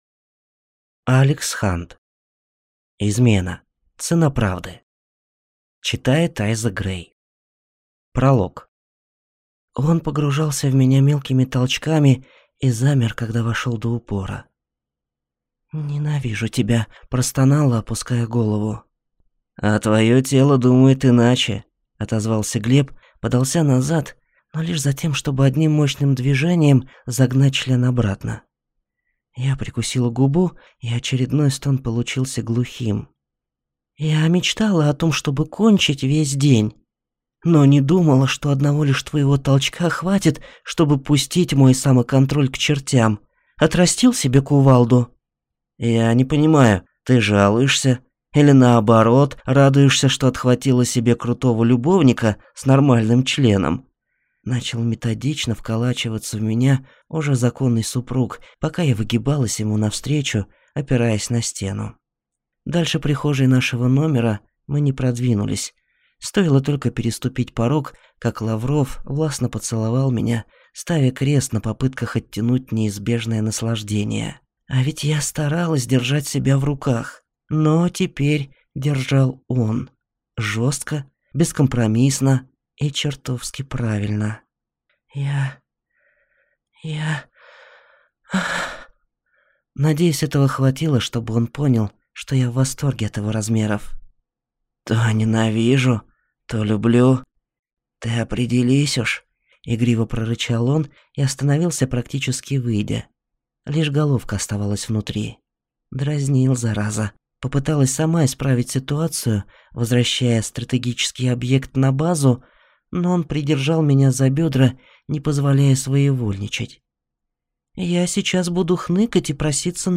Аудиокнига Измена. Цена правды | Библиотека аудиокниг